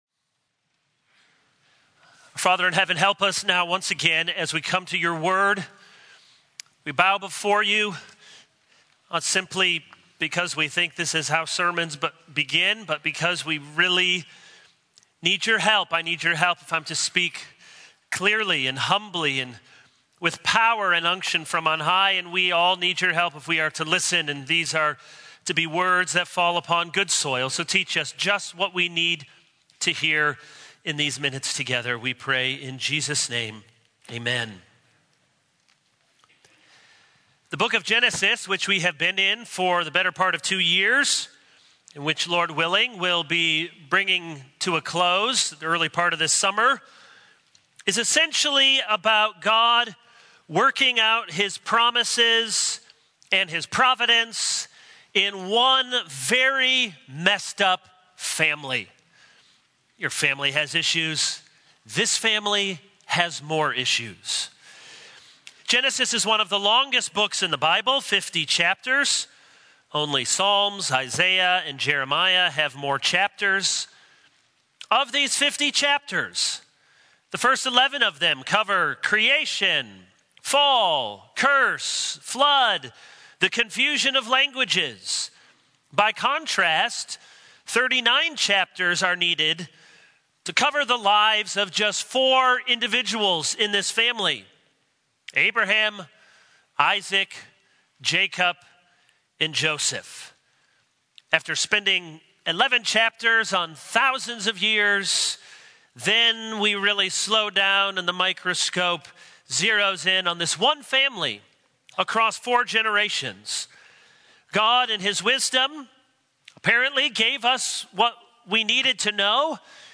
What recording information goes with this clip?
March 27, 2022 | Sunday Morning